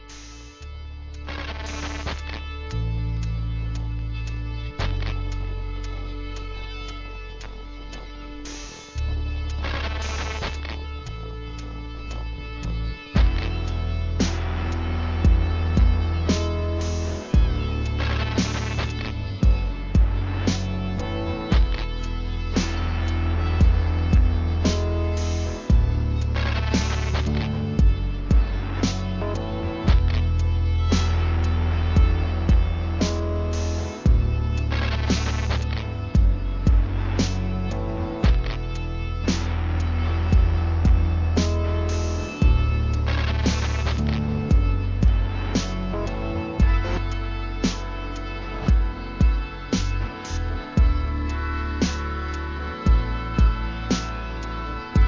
Abstract, Ambient, Experimental!